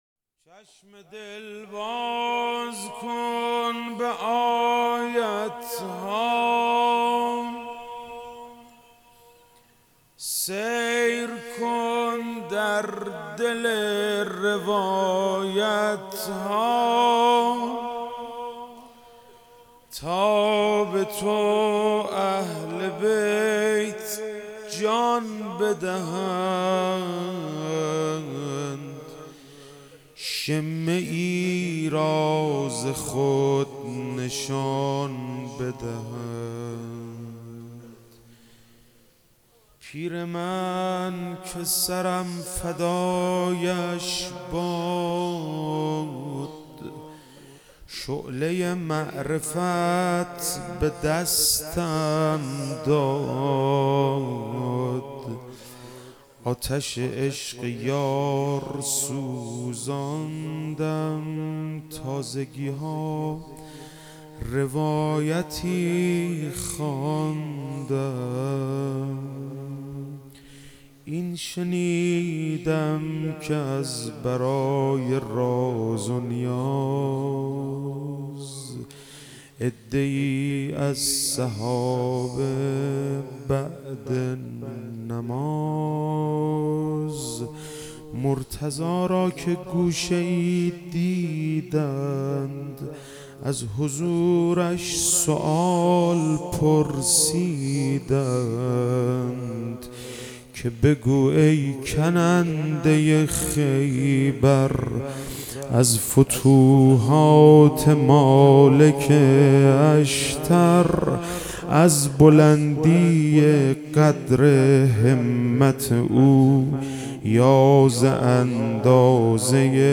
روضه
شب تاسوعا محرم 98